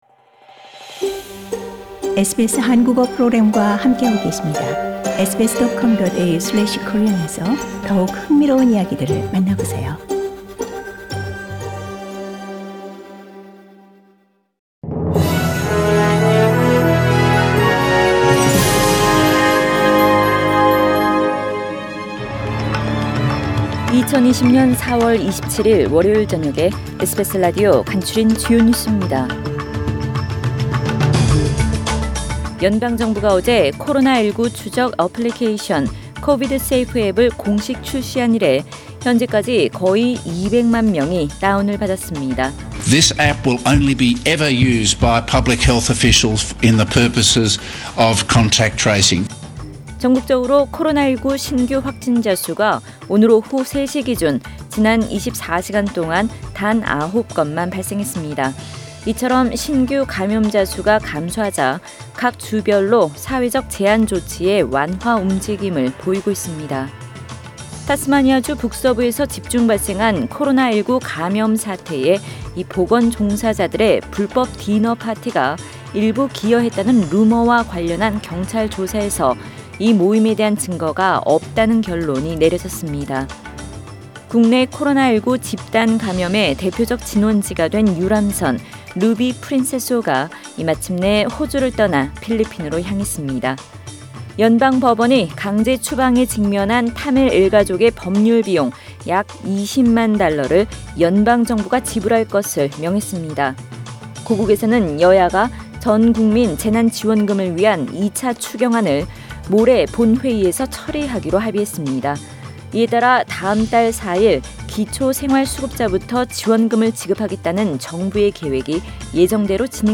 SBS 한국어 뉴스 간추린 주요 소식 – 4월 27일 월요일
2020년 4월 27일 월요일 저녁의 SBS Radio 한국어 뉴스 간추린 주요 소식을 팟 캐스트를 통해 접하시기 바랍니다.